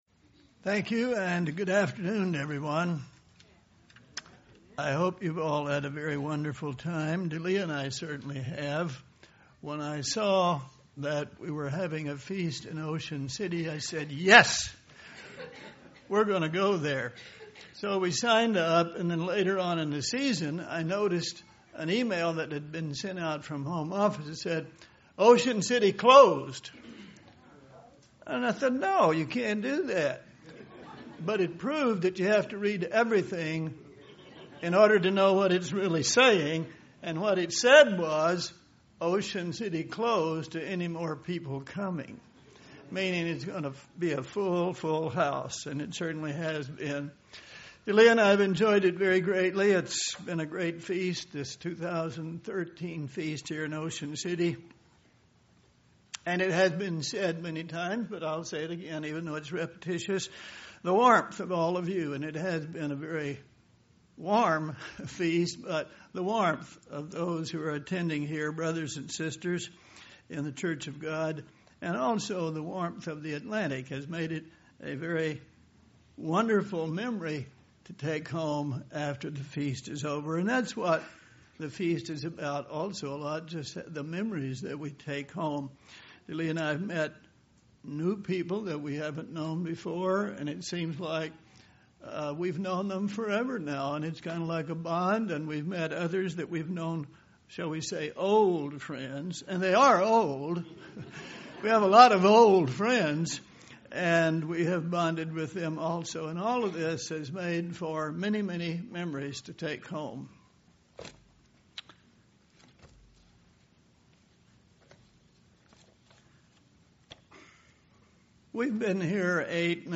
This sermon was given at the Ocean City, Maryland 2013 Feast site.